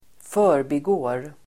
Uttal: [f'ö:rbi:gå:r]